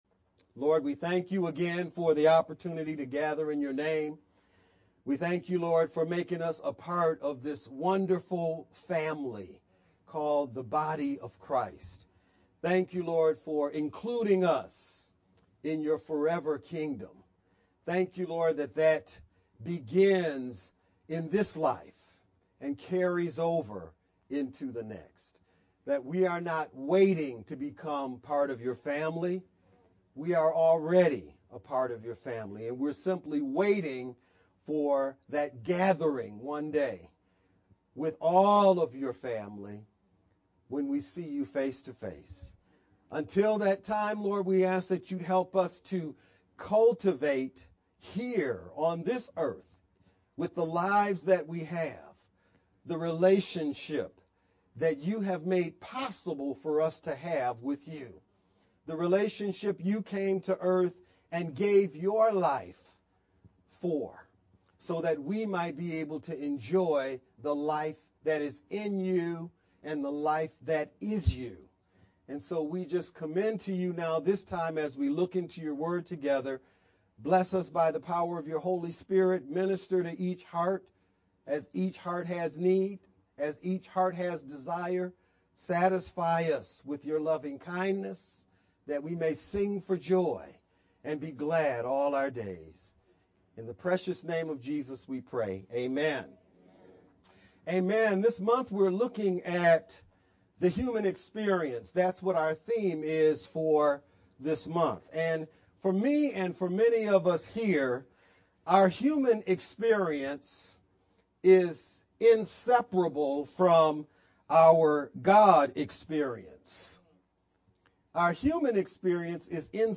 VBCC-Sermon-only-edited_Converted-CD.mp3